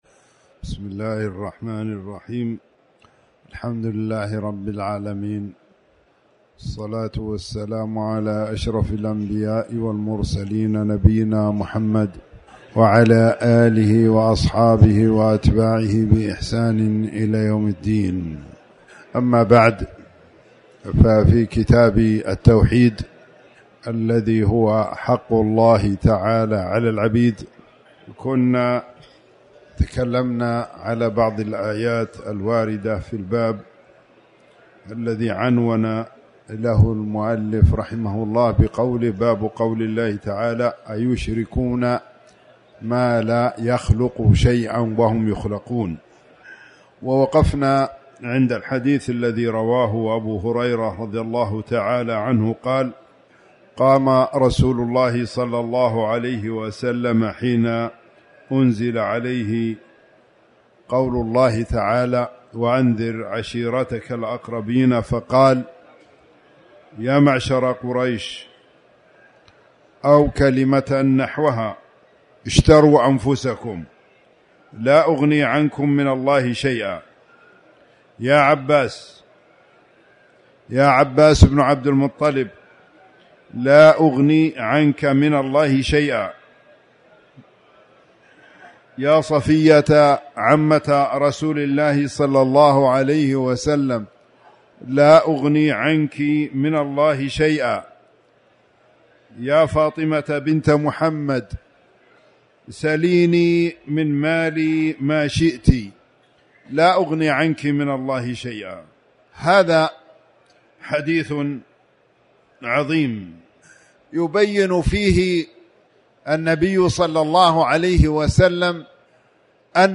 تاريخ النشر ١٨ رمضان ١٤٣٩ هـ المكان: المسجد الحرام الشيخ